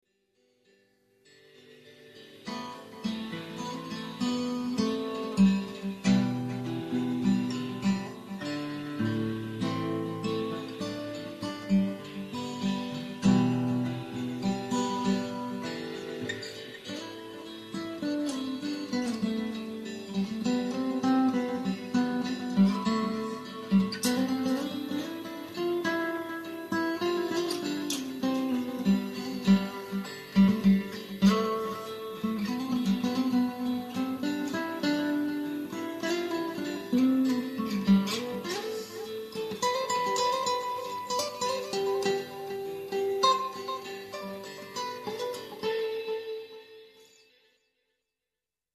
Соло
:ps: записал без разминки :gg: